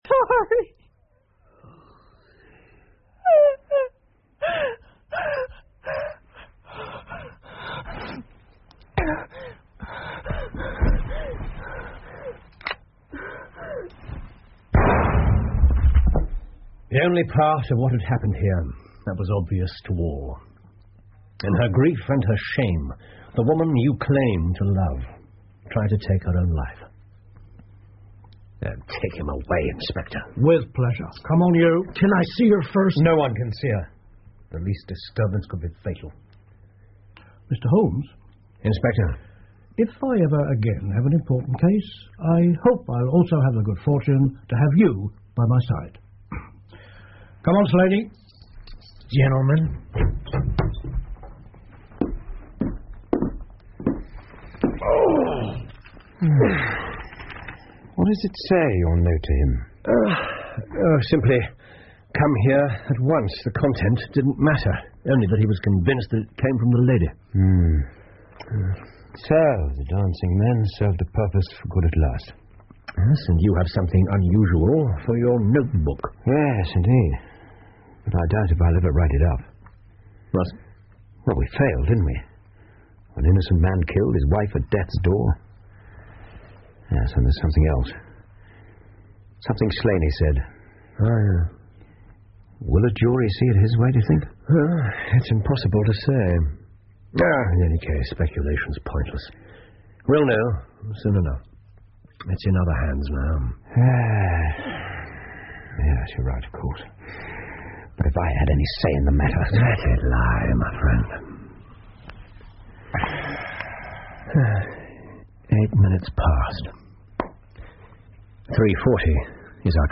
福尔摩斯广播剧 The Dancing Men 9 听力文件下载—在线英语听力室